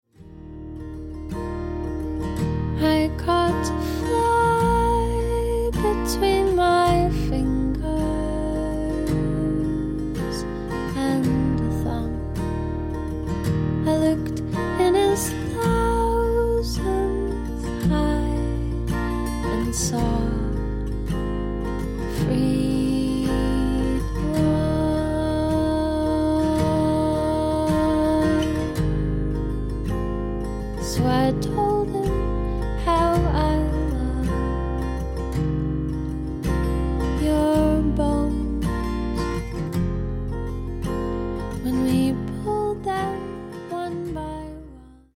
彼女が歌うのは、おやすみ前の読み聞かせのような歌。